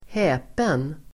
Uttal: [²h'ä:pen]